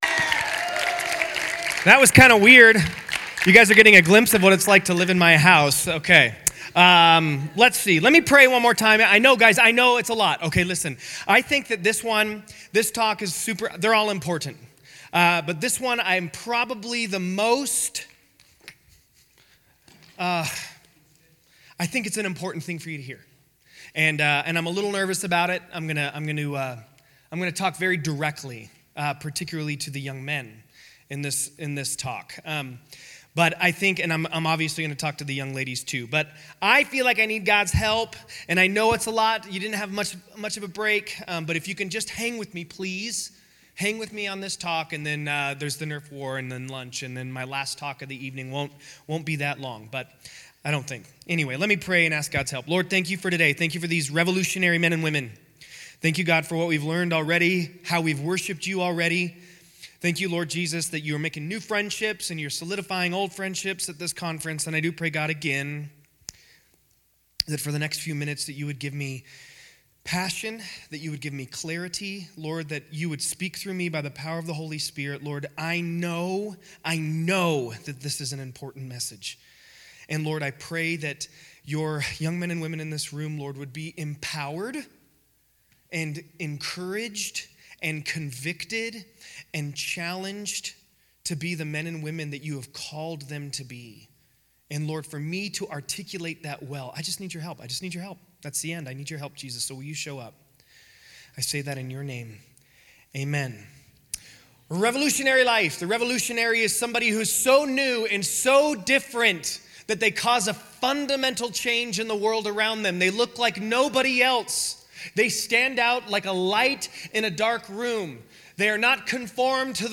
Winter Teen Conference - Teaching 3 - Summitview Church